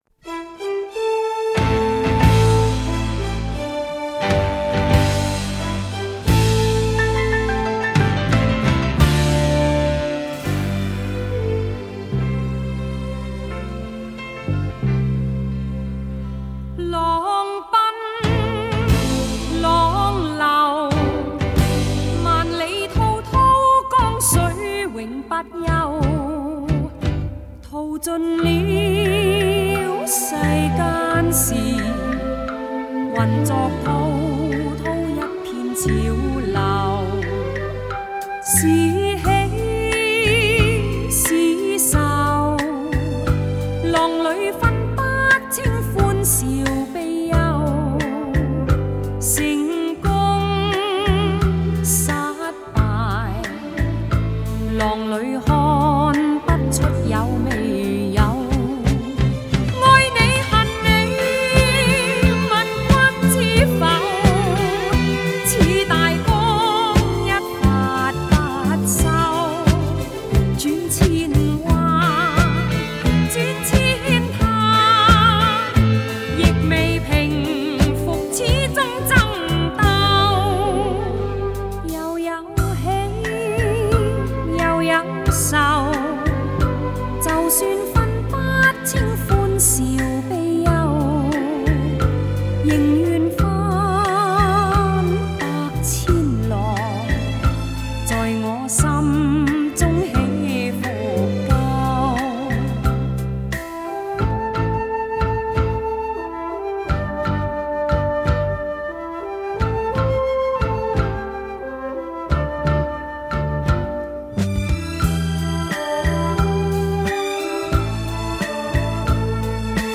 香港无线电视剧集主题曲
由于母带年代久远,部分音质或有损坏.在重新整理后,现已为最佳效果.